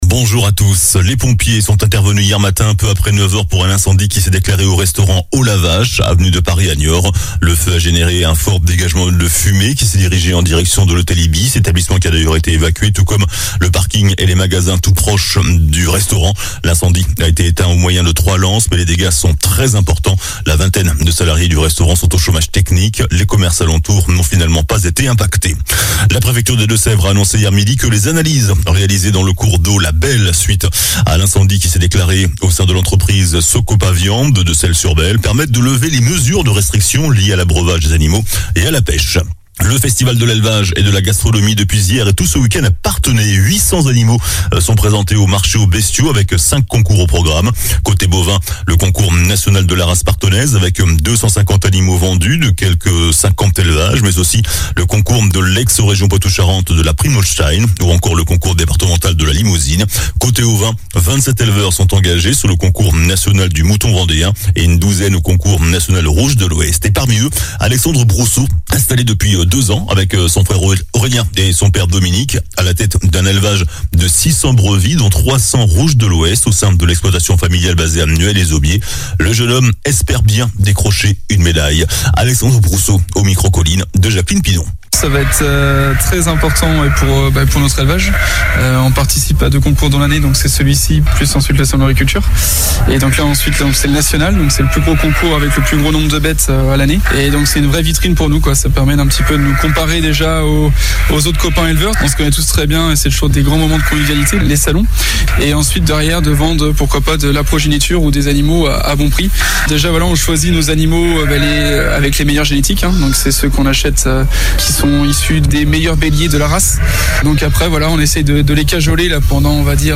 Journal du samedi 23 septembre
infos locales